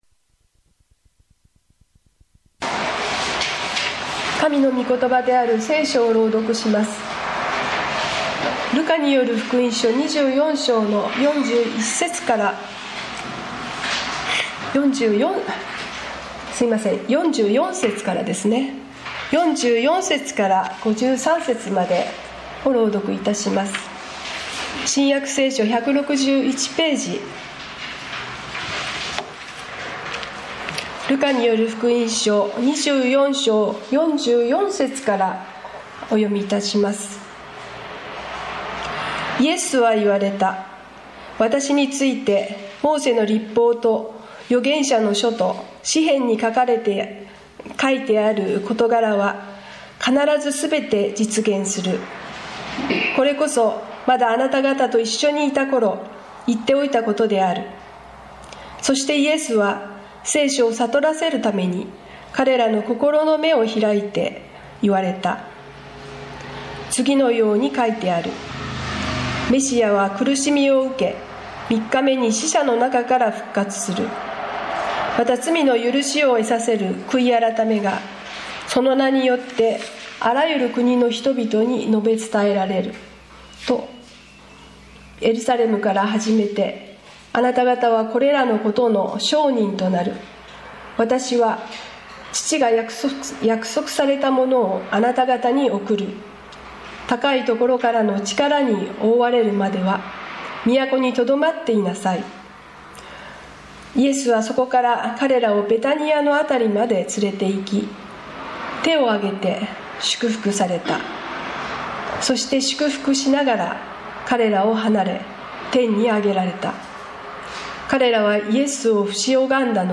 日曜 朝の礼拝